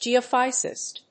アクセント・音節gèo・phýsicist
音節ge･o･phys･i･cist発音記号・読み方ʤìːəfɪ́zəsɪst